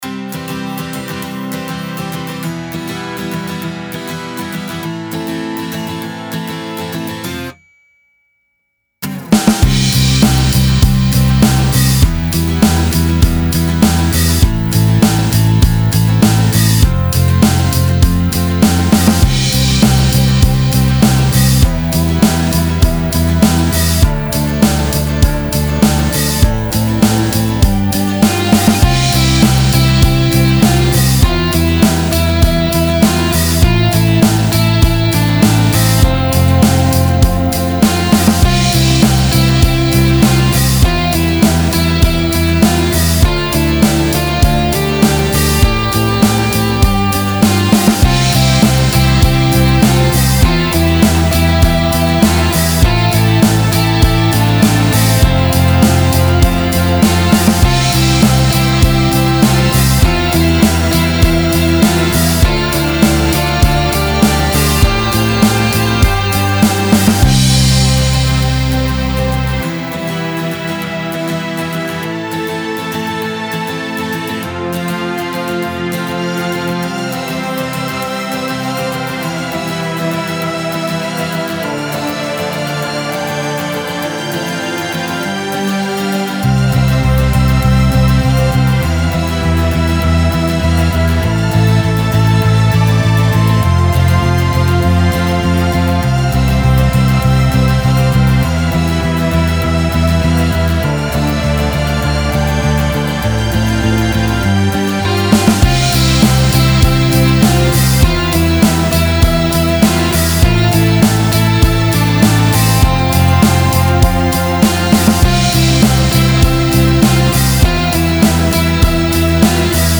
Style Style Rock
Mood Mood Relaxed, Uplifting
Featured Featured Acoustic Guitar, Bass, Drums +3 more
BPM BPM 100